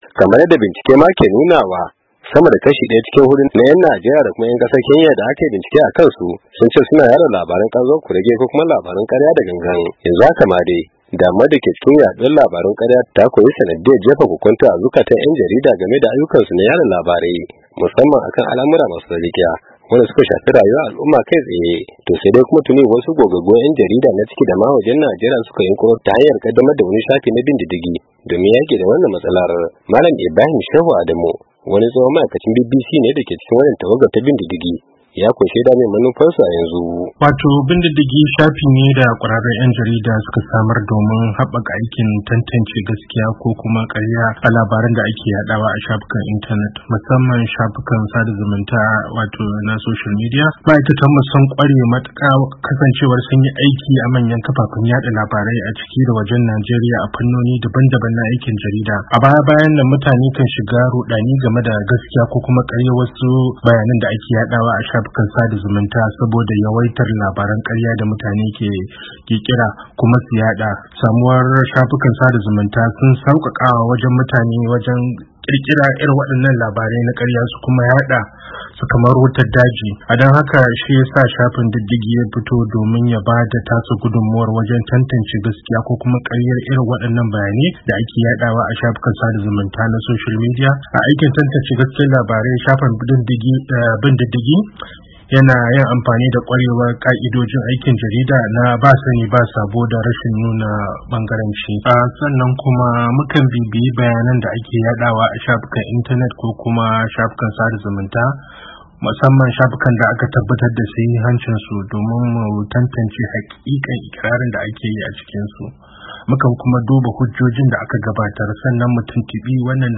cikakken rahoton